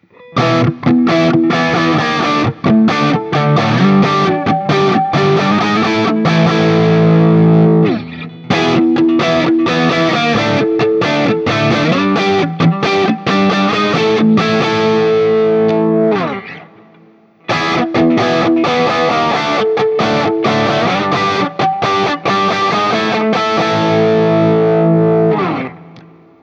JCM-800
I found the neck pickup to be a bit woofy at times, and remember this is with that pickup lowered a great deal because it is the hotter of the two pickups.
This is the first time I used my new Axe-FX III for recording which I did direct to Audacity to my Mac Pro.
For each recording I cycle through the neck pickup, both pickups, and finally the bridge pickup. All knobs on the guitar are on 10 at all times.
Guild-TBird-ST-JCM800-A.wav